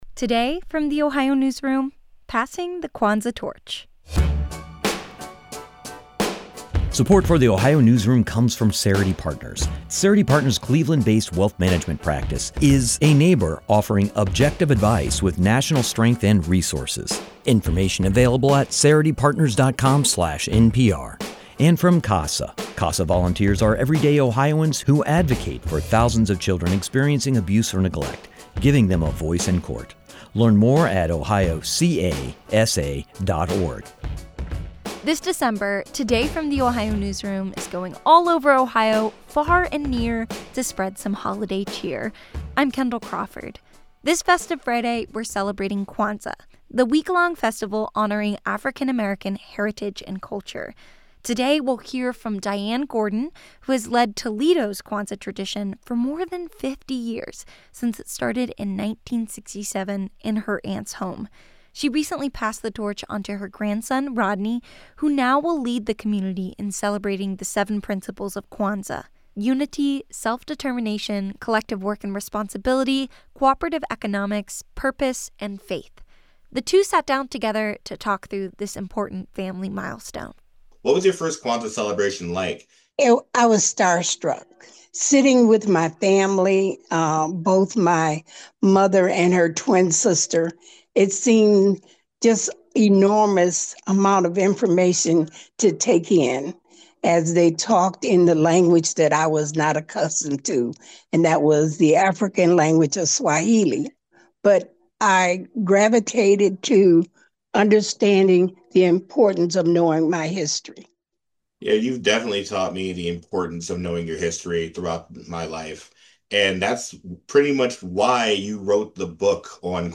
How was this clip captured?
Their conversation has been lightly edited for brevity and clarity.